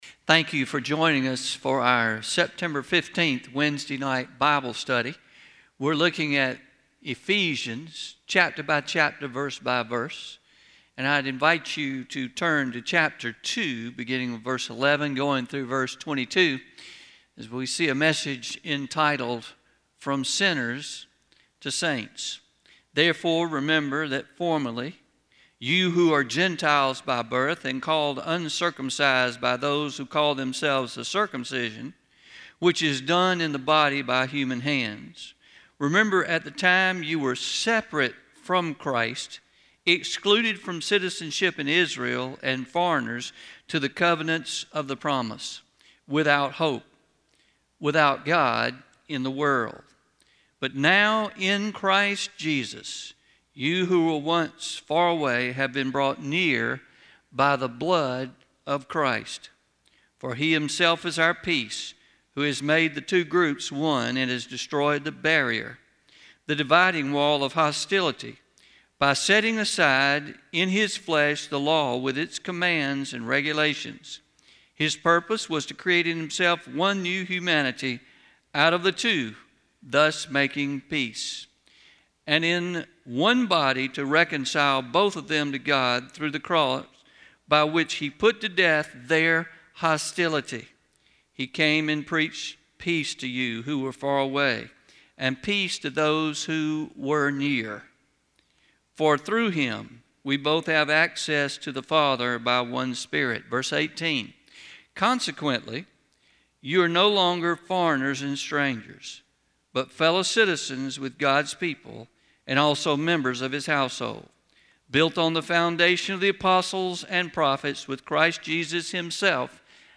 09-15-21PM Sermon – From Sinners to Saints